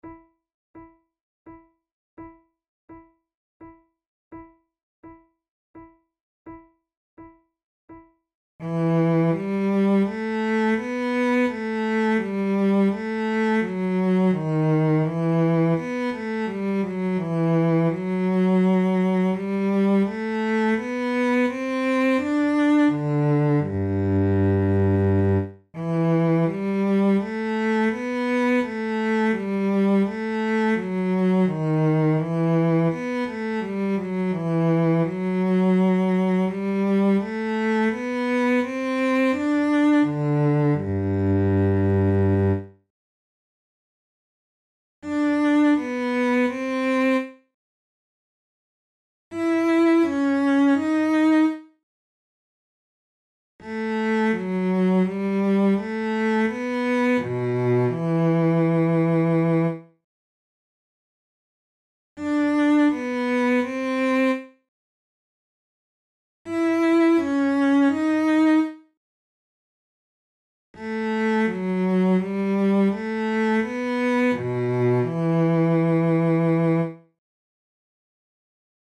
KeyE minor
Tempo84 BPM
Baroque, Sonatas, Written for Flute